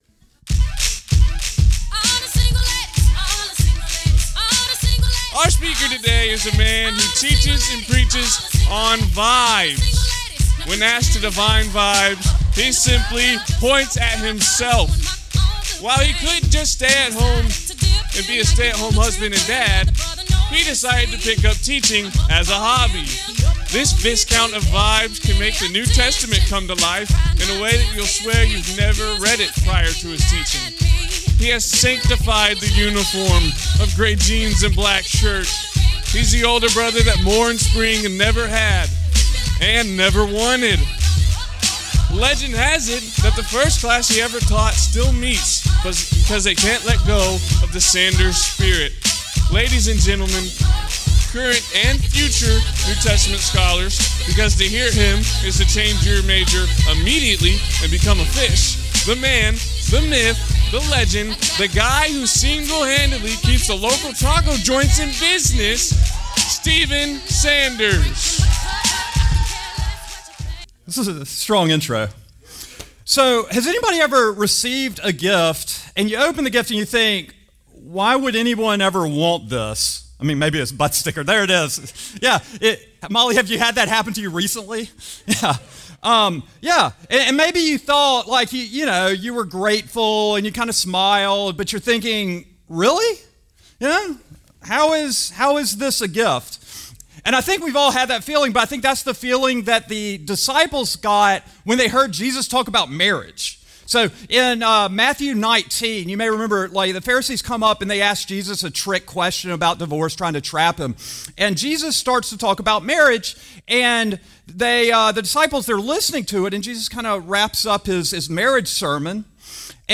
Criswell College Wednesdays Chapel.